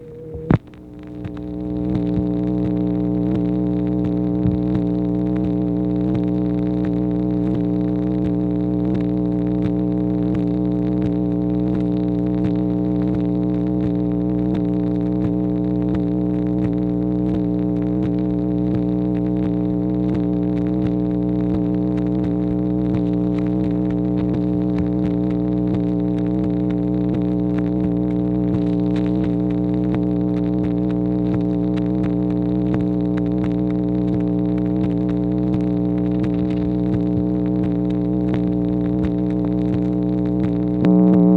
MACHINE NOISE, July 10, 1964
Secret White House Tapes | Lyndon B. Johnson Presidency